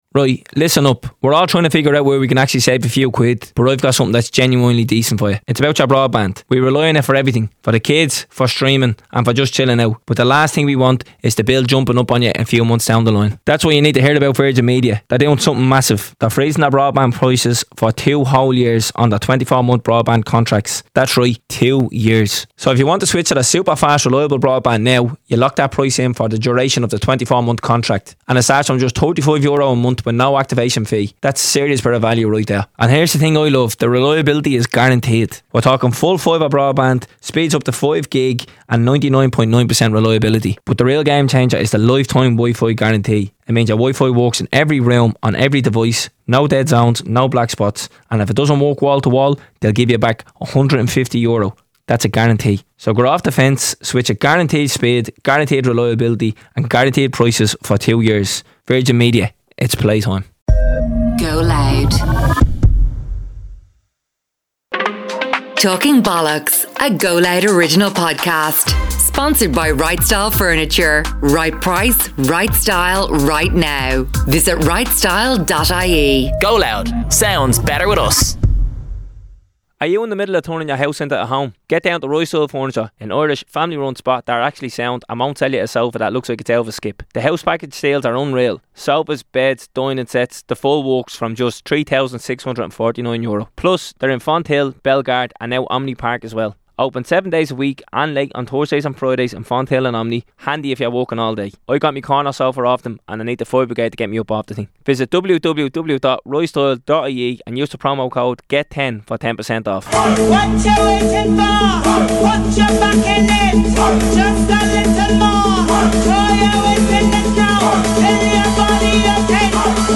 Two lads from the inner city of Dublin sitting around doing what they do best, talking bollox.